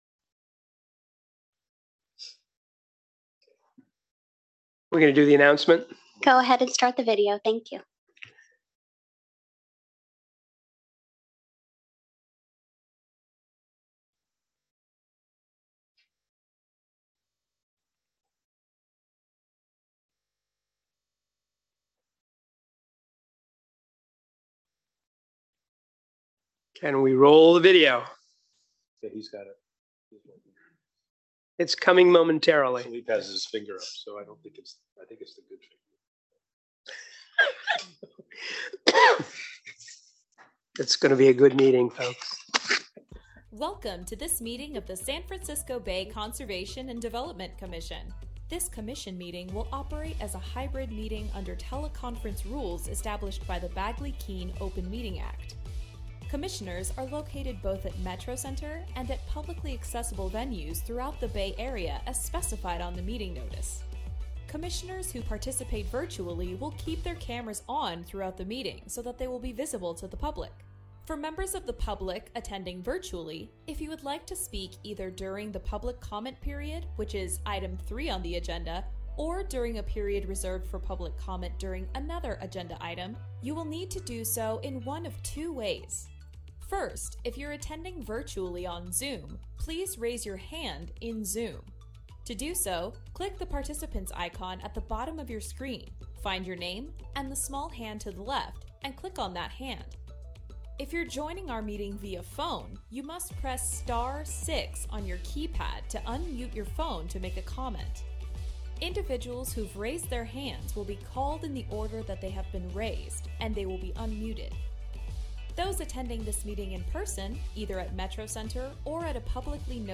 October 19, 2023 Commission Meeting | SF Bay Conservation & Development